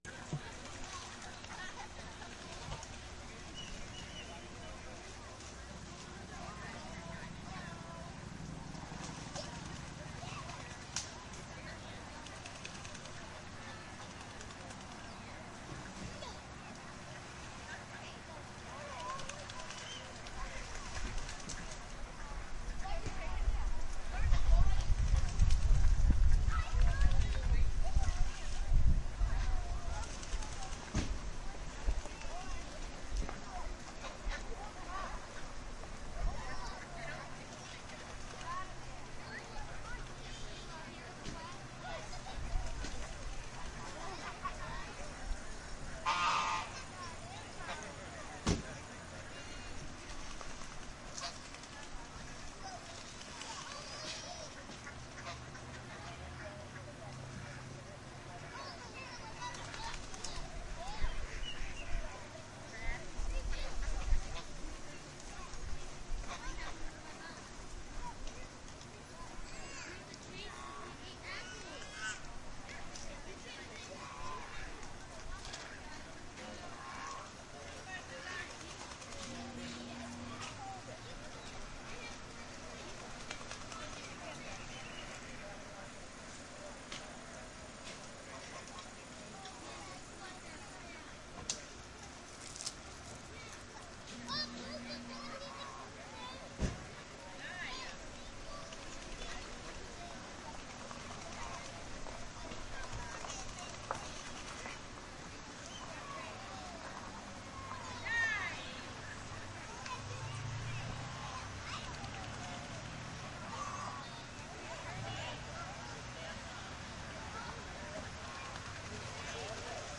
鸭子池塘氛围1
描述：记录了儿童在鸭池边喂鸭子的情景。 声音包括鸭子溅水、嘎嘎叫和拍打翅膀的声音，以及儿童和家庭的谈话声
Tag: 儿童 池塘 襟翼 嘎嘎 spash 翅膀